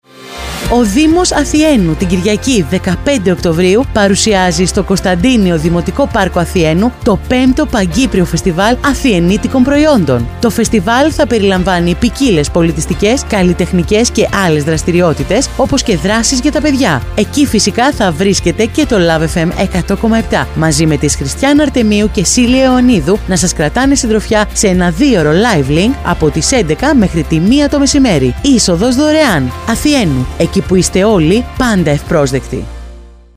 DIMOS-ATHIENOUY-LIVE-LINK-TRAILER-OCT-2023-.mp3